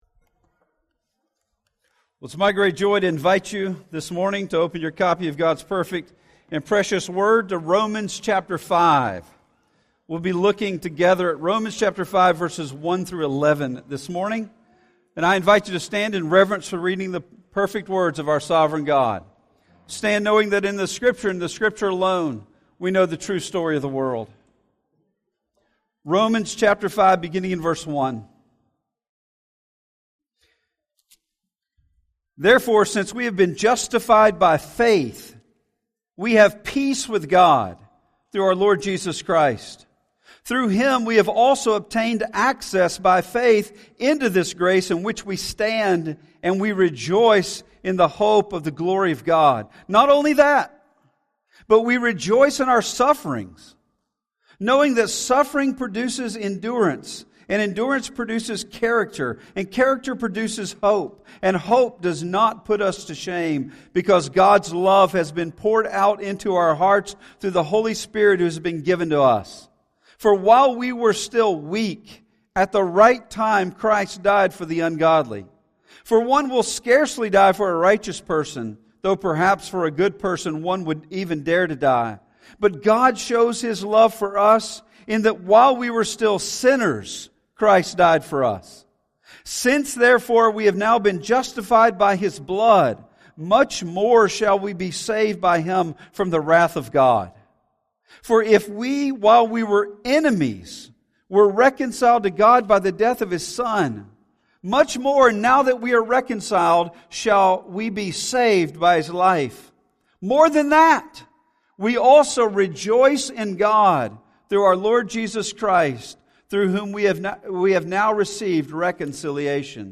In this sermon from our series "The Gospel," we begin seeing the beautiful implications of justification for our lives.